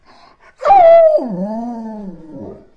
描述：我的阿拉斯加雪撬犬Igor在等待晚餐时的录音。马拉穆特犬以其传神的发声能力而闻名。在我的厨房里用Zoom H2录制的。
标签： 树皮 赫斯基 雪橇 雪橇狗 咆哮 呻吟
声道立体声